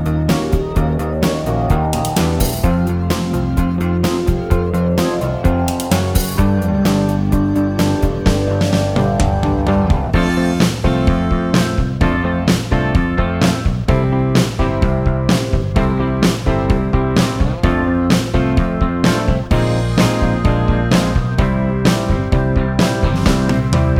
No Backing Vocals Soundtracks 5:06 Buy £1.50